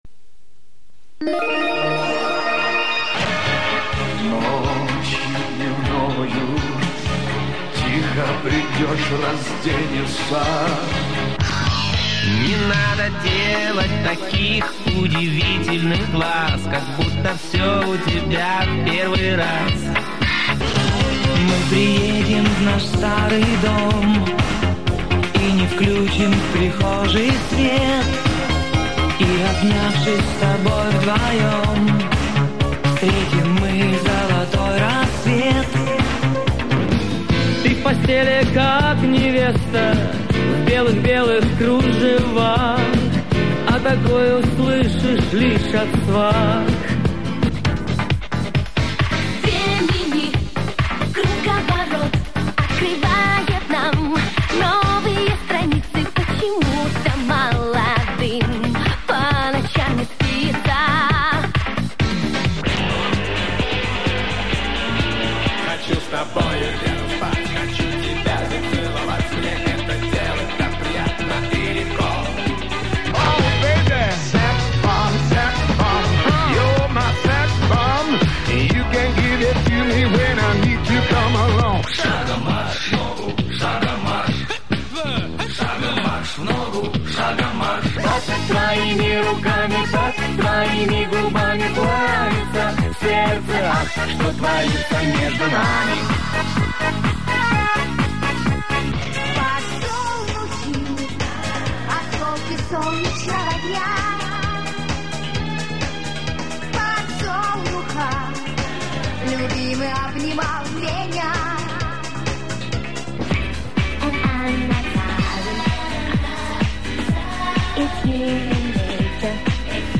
Предлагаю вашему вниманию музыкальные заставки придумывал и записывал сам на магнитоле "Panasonic CT980". Оцифровка с кассет.
заставки